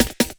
41 LOOPSD2-L.wav